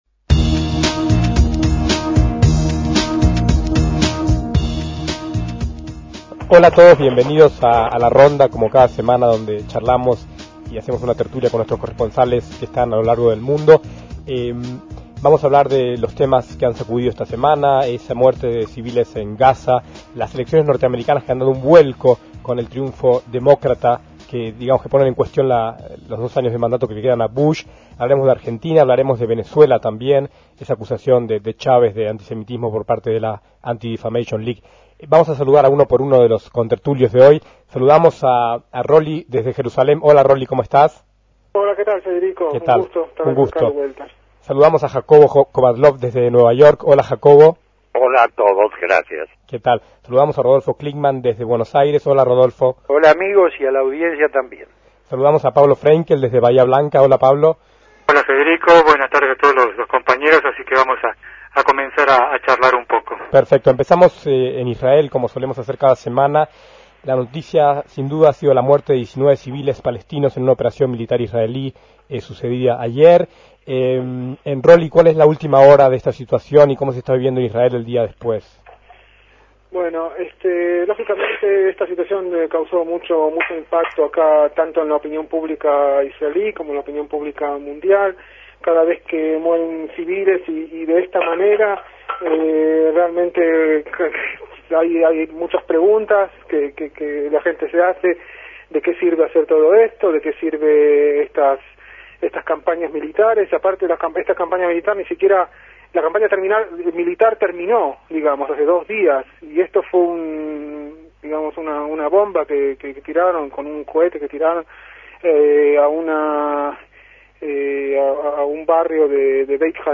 A esta mesa redonda de corresponsales internacionales acudieron